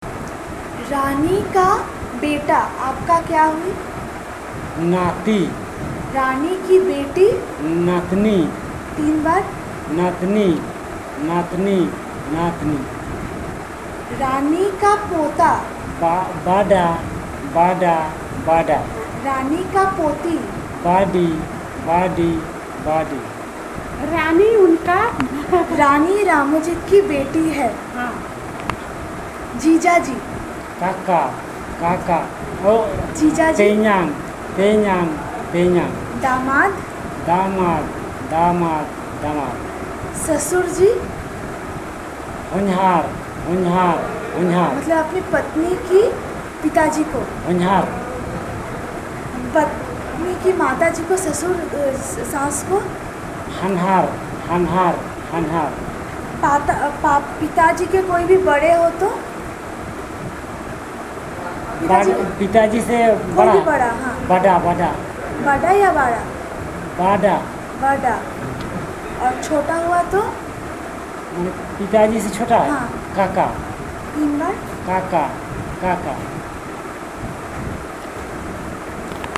The researcher uses Hindi as the language of input, the informant then translates the term in the target language, i.e. Birjia.